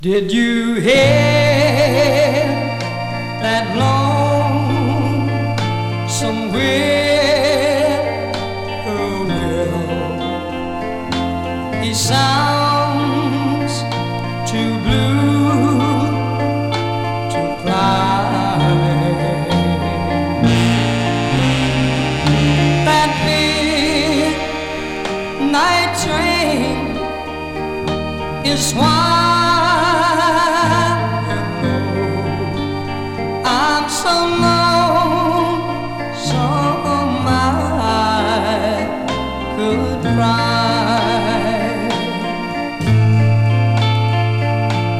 Rock, Pop, Swamp　USA　12inchレコード　33rpm　Stereo